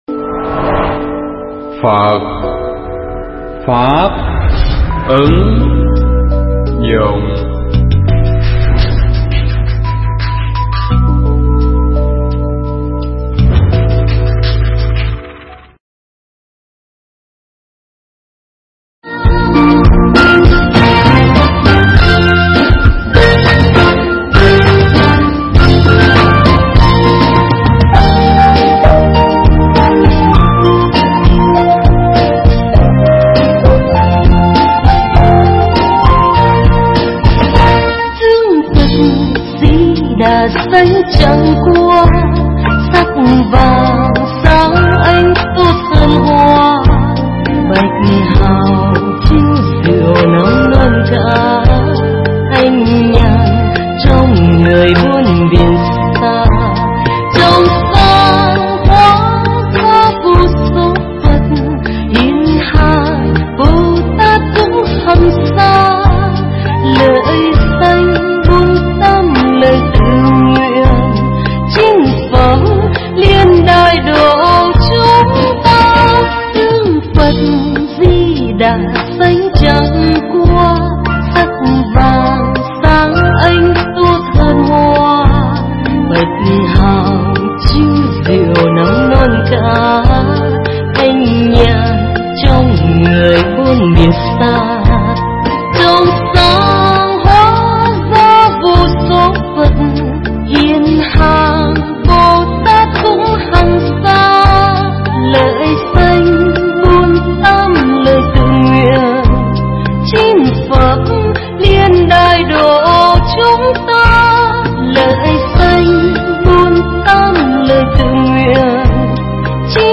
Pháp thoại Tịnh Độ Con Đường Tu Tắt
thuyết pháp tại chùa Hoằng Linh - xã An Nhơn Tây, huyện Củ Chi, TP.HCM